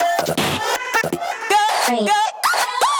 这些循环专为地下电子音乐打造，容量为 145 MB，包含 24 位 WAV 格式、160 BPM 的音频文件，可直接导入你的项目。
• 160 BPM